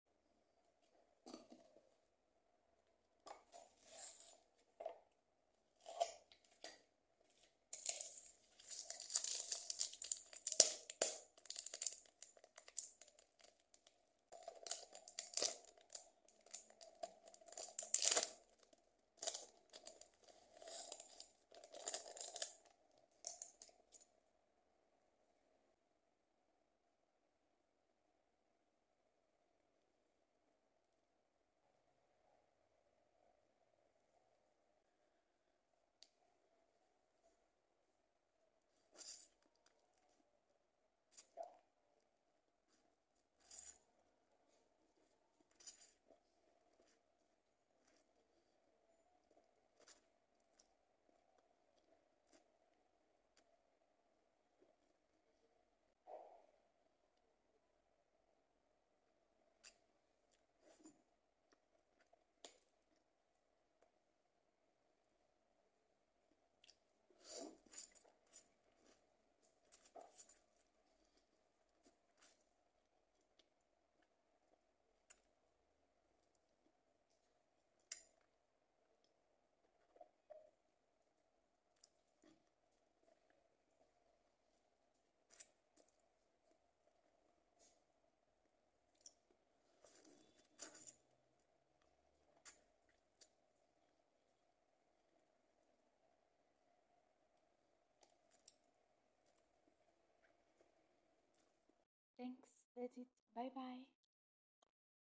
Cooking and Eating Lamen 👩‍🍳🍜😋 sound effects free download
Mukbang ASMR